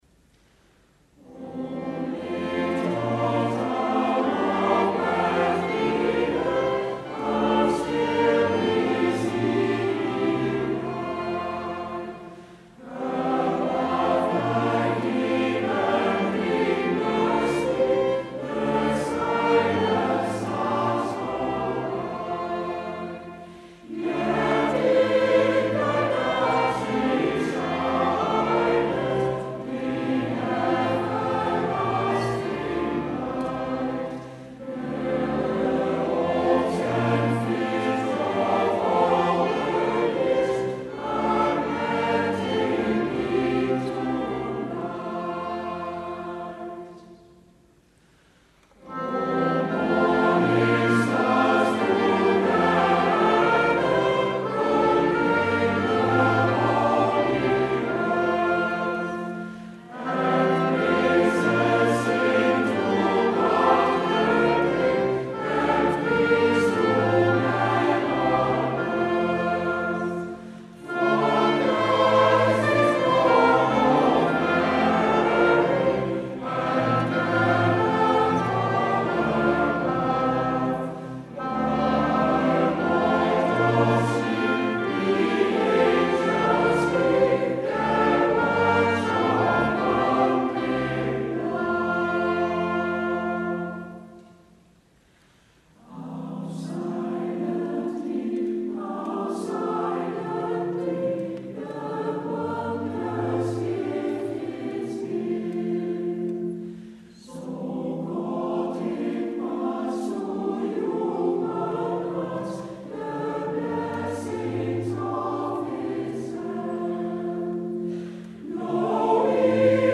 Op deze pagina zijn diverse live opnamen te horen,
...geen studio kwaliteit...
Amstellandse Cantorij
live opname van de kerstconcerten in 2009  en 2010:
O little  town of Bethlehem - (with congregation)